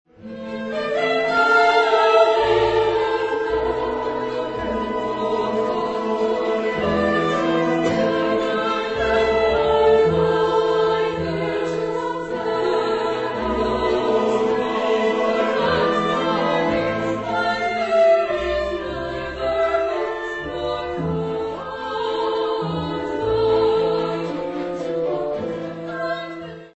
Genre-Style-Form: Oratorio ; Romantic ; Sacred
Type of Choir: SATB  (4 mixed voices )
Instruments: Piano (1)